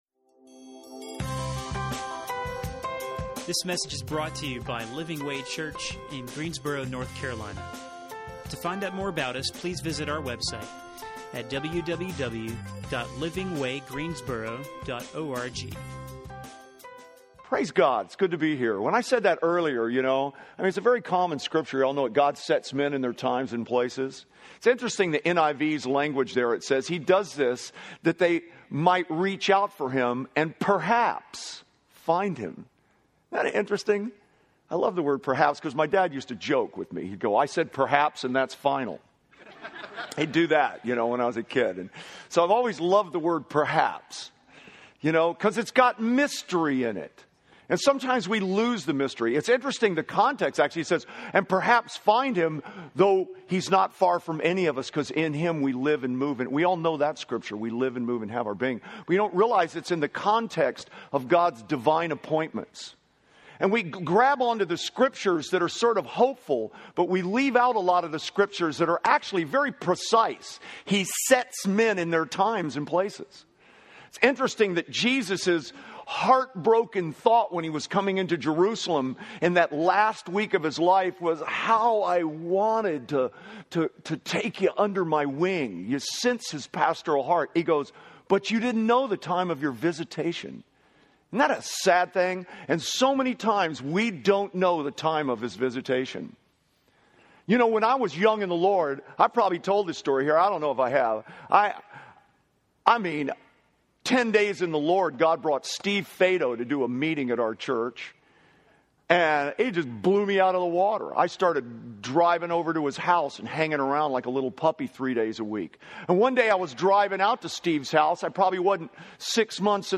Stand Alone Sermons